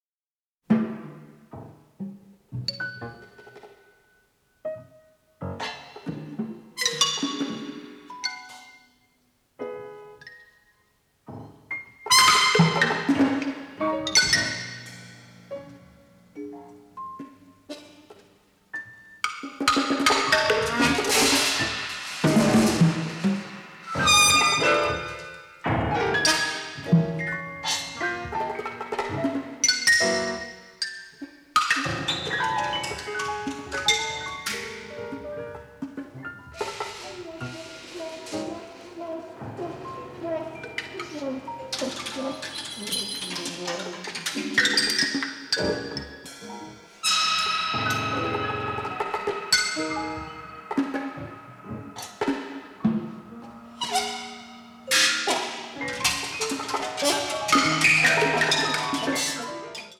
avantgarde score
It had less music, but it was all in stereo.